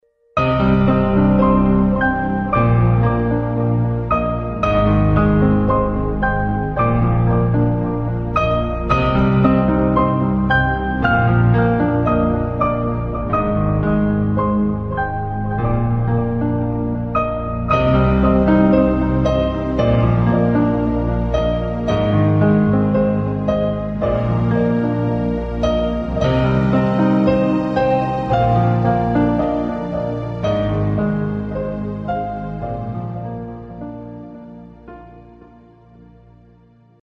Печальные и грустные звуки пианино